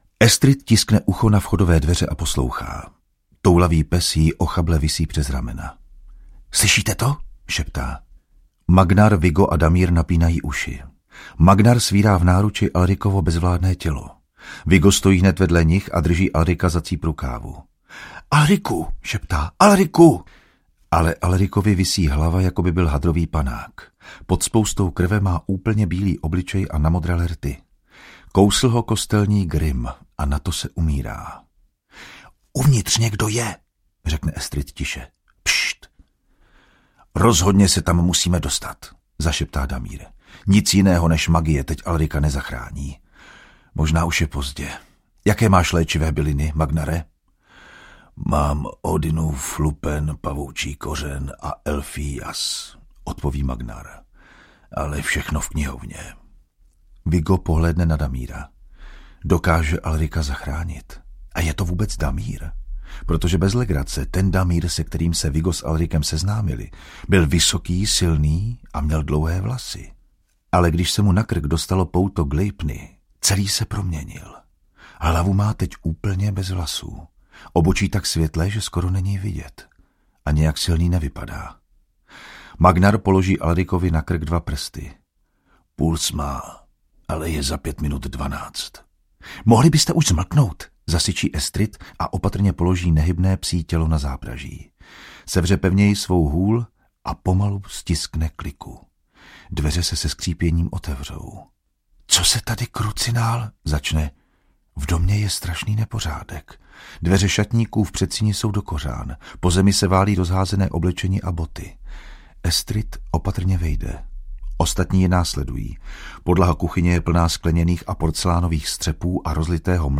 PAX 3, 4: Dívka ze záhrobí, Neviditelný zloděj audiokniha
Ukázka z knihy